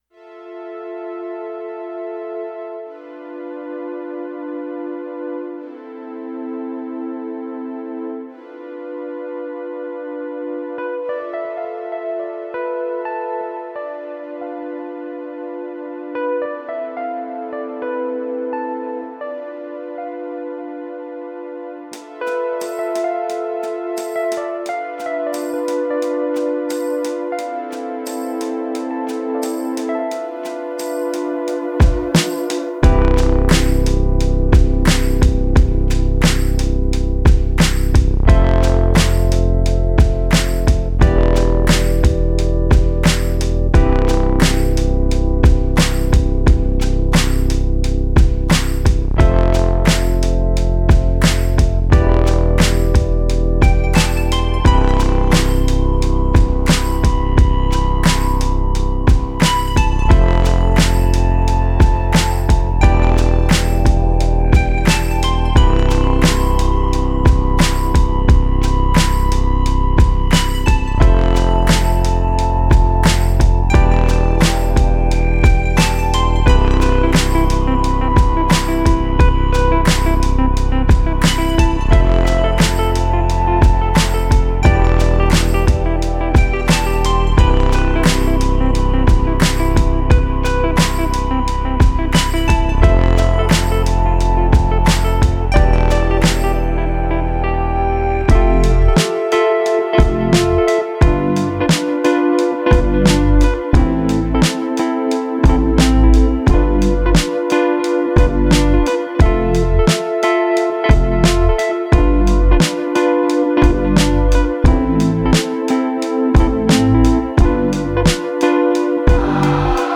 HACEDOR DE RITMOS Y PINCHA DISCOS